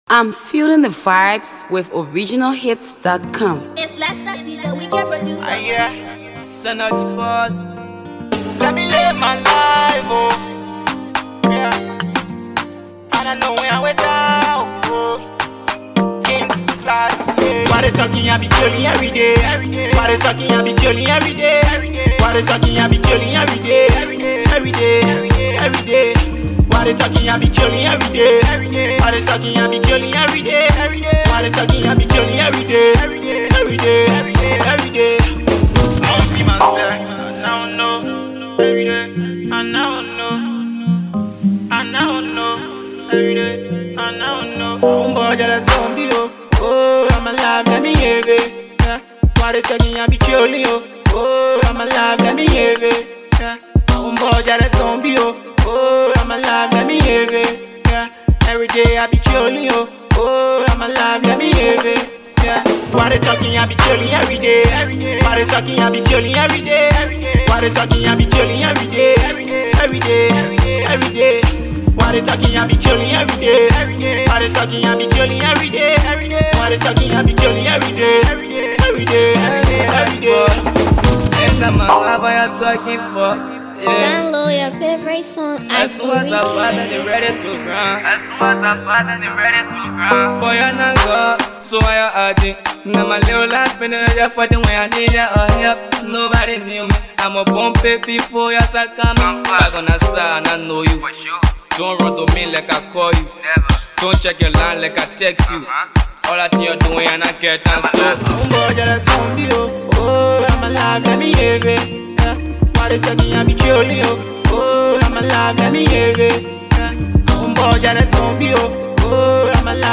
talented vocalist
Afro Pop